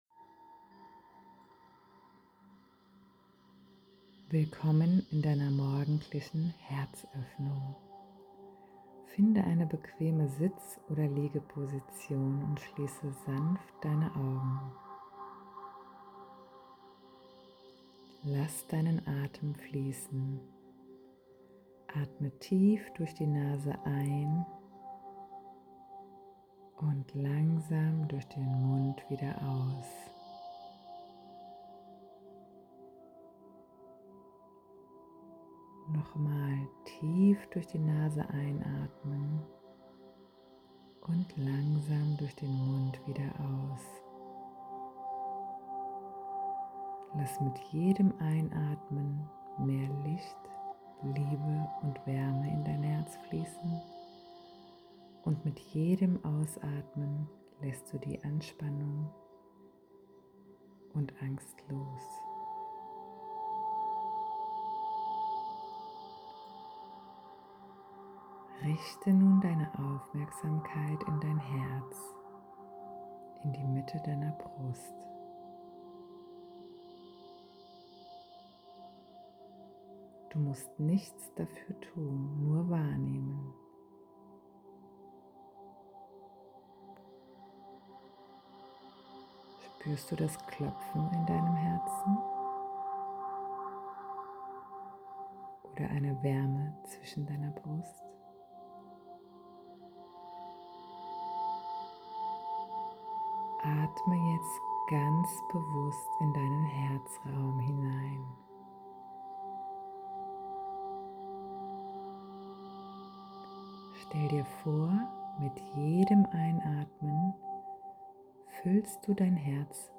Meditationen